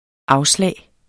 Udtale [ -ˌslæˀj ]